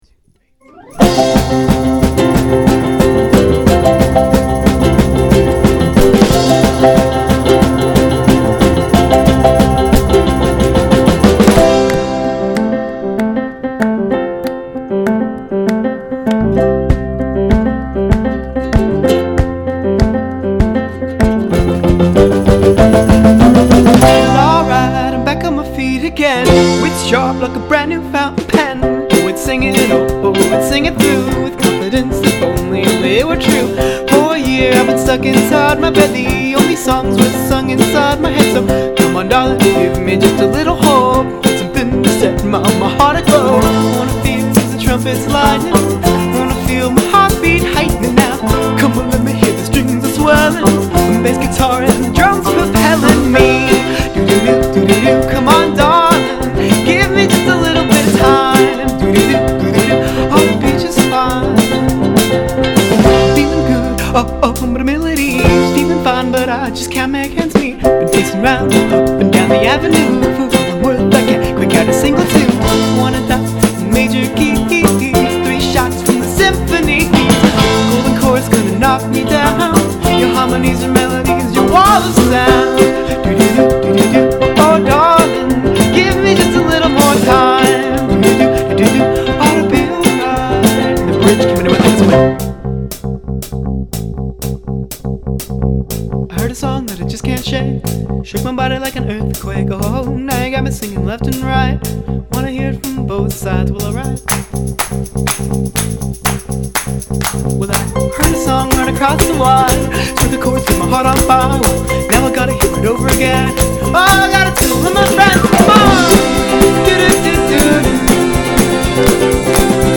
Indiepop, battimani e un po' di marketing